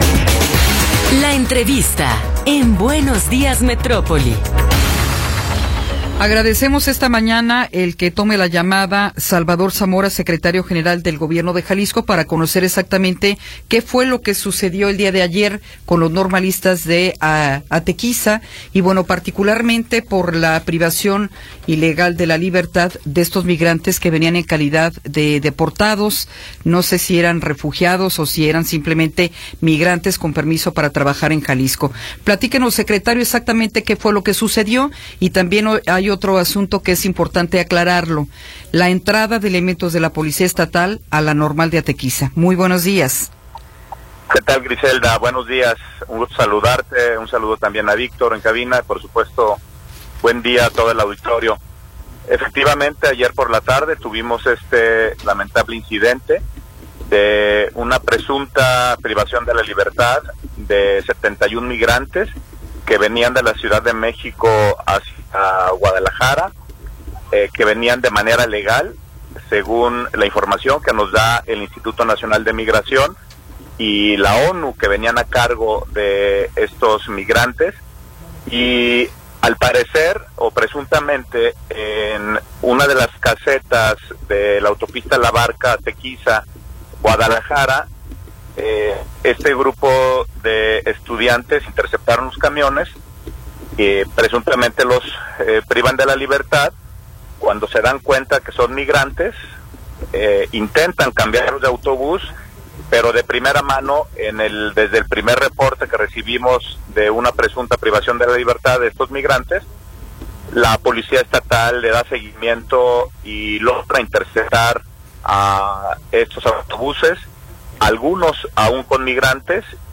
Entrevista con Salvador Zamora Zamora